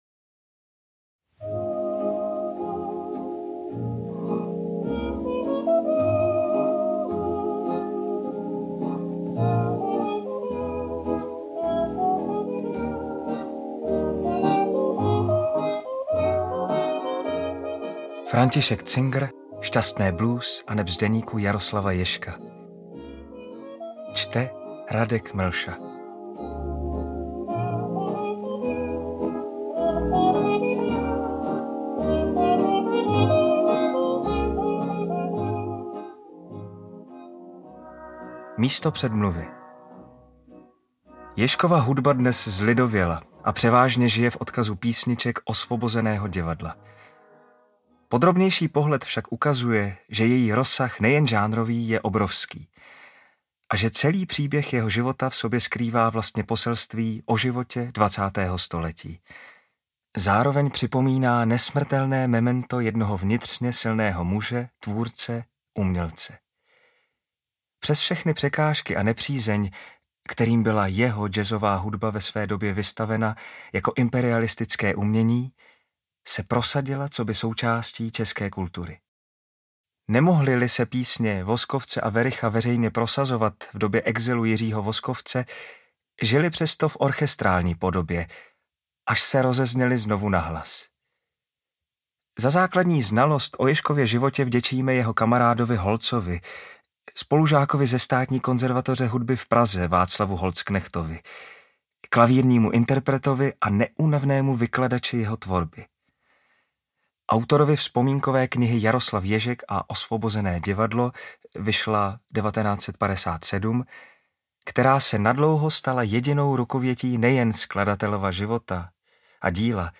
Tato audioknižní verze velmi úspěšné memoárové knihy Františka Cingera (již vyšla ve třech vydáních), uznávaného popularizátora odkazu Osvobozeného divadla, čerpá z Ježkových dopisů, méně známých archivních materiálů a odhaluje i jeho soukromý a milostný život....